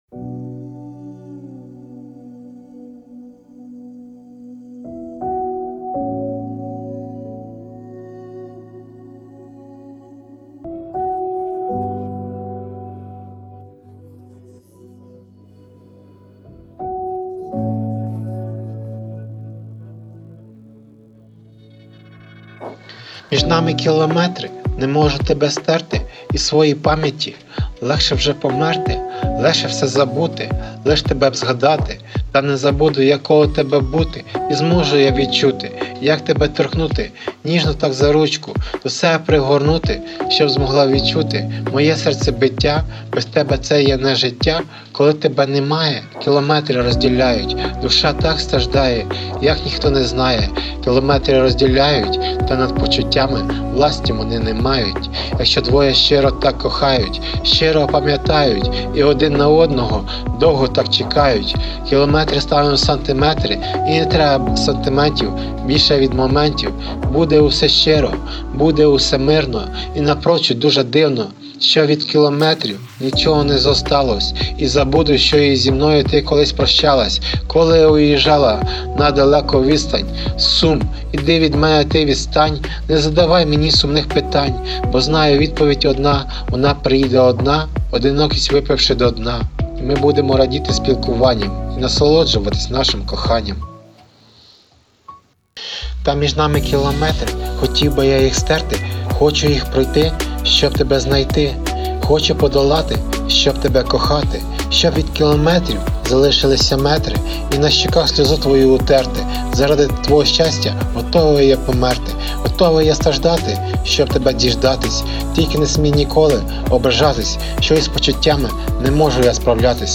ТИП: Музика
СТИЛЬОВІ ЖАНРИ: Ліричний
ВИД ТВОРУ: Пісня